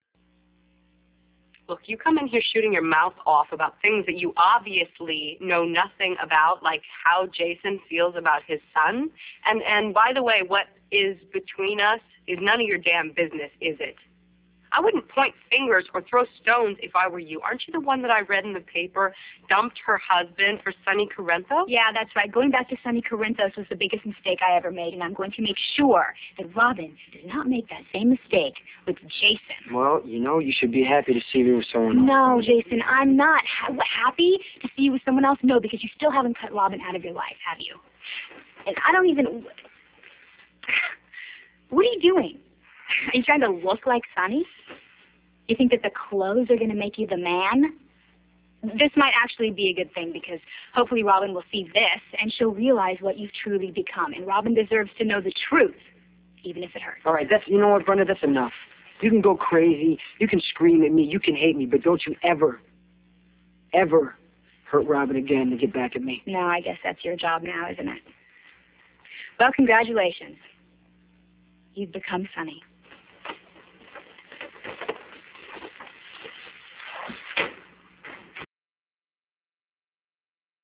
were recorded in less than great quality.